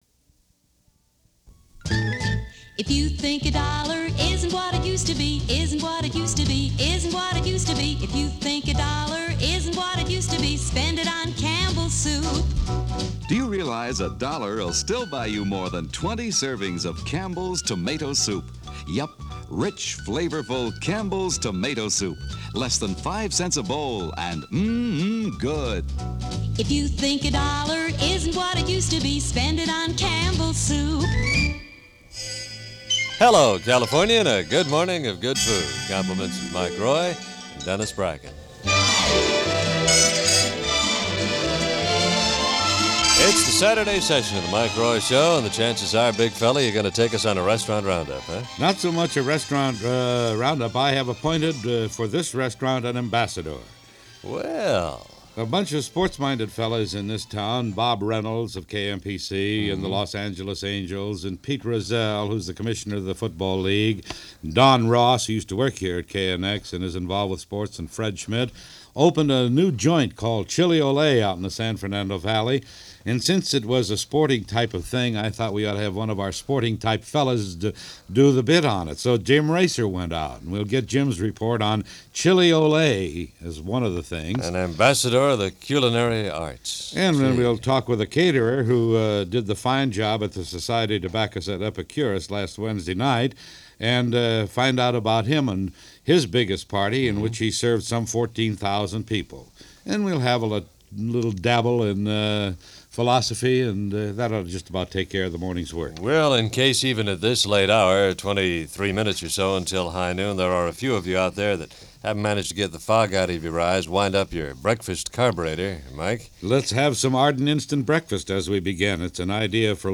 The opening featured a virtual who’s who in College Football along with a number of other sports teams around the Southern California area.